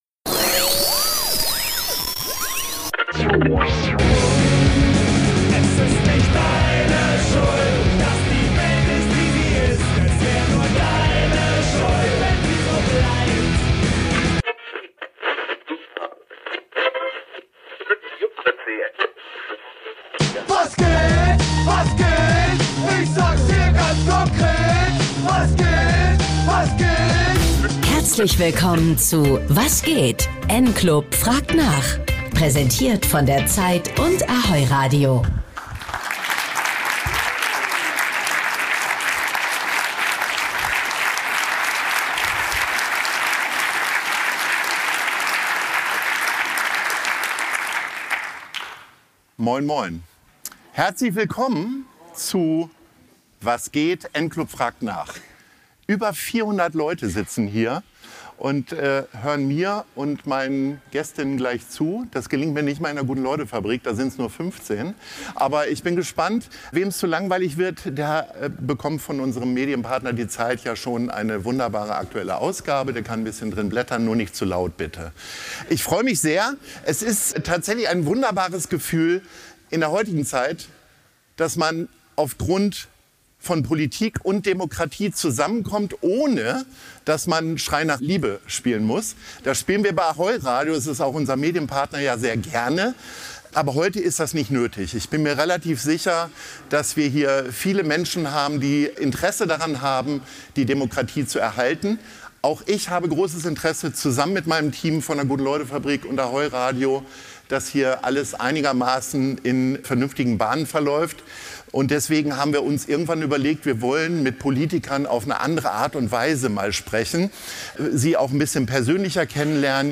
Im Gespräch geht es um Migration, AfD-Aufwind, politische Werte und die Zukunft der Grünen Mehr